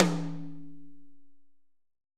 R.AMBTOMH2.wav